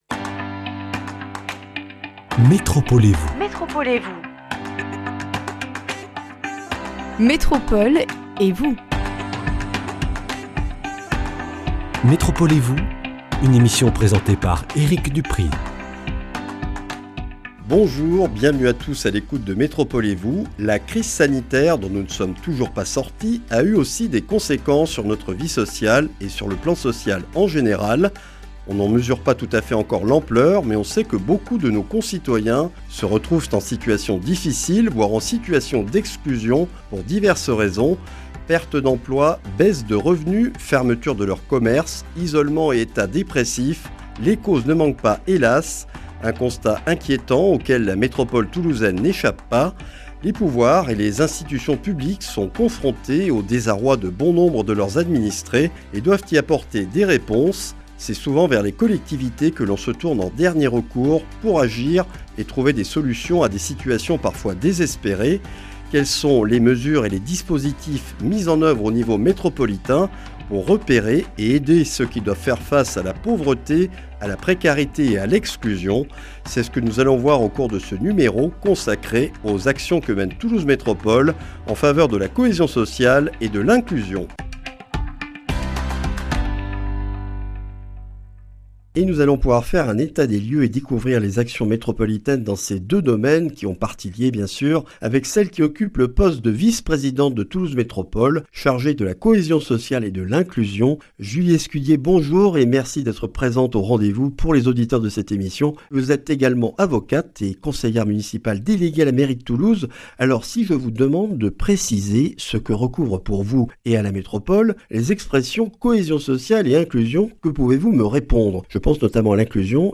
Julie Escudier, conseillère municipale déléguée de Toulouse, est vice-présidente de Toulouse Métropole chargée de la Cohésion sociale et de l'Inclusion.